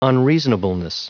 Prononciation du mot unreasonableness en anglais (fichier audio)
Prononciation du mot : unreasonableness